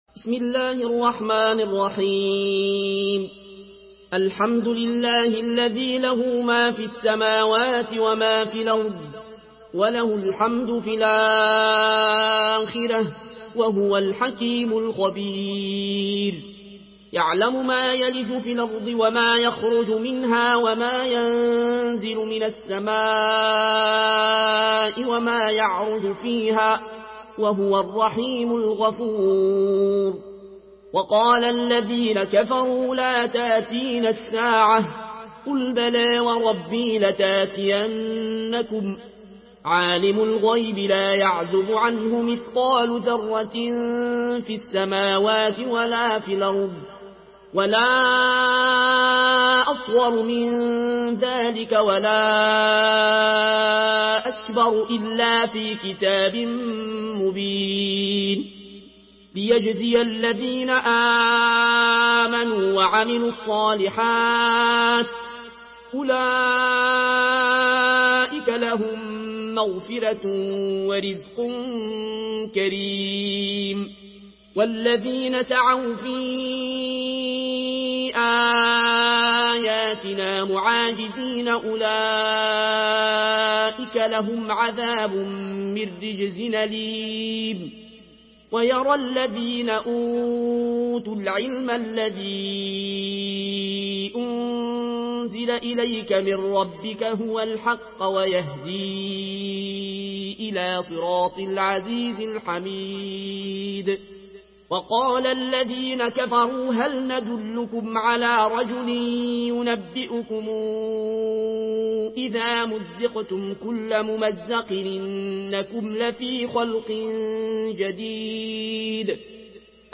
سورة سبأ MP3 بصوت العيون الكوشي برواية ورش
مرتل ورش عن نافع من طريق الأزرق